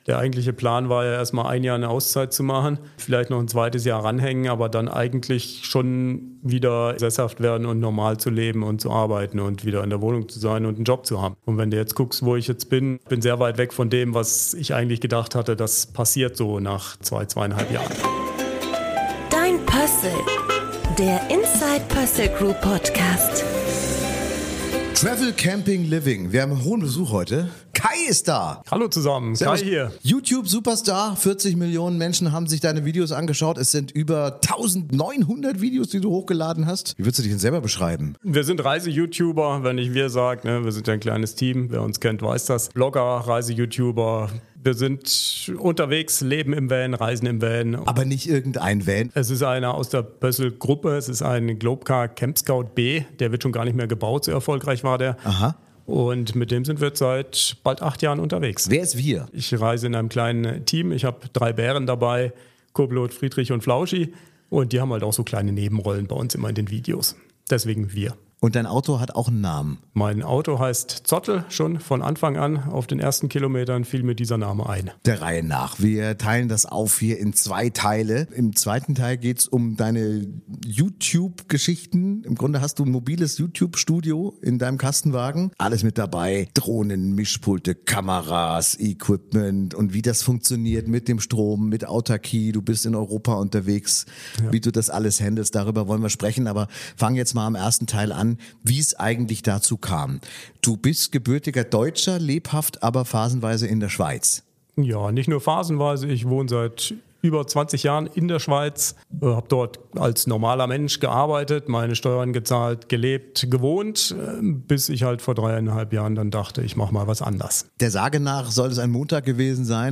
Ein Talk über Aufbruch, Selbstbestimmung – und die Kunst, einfach loszufahren.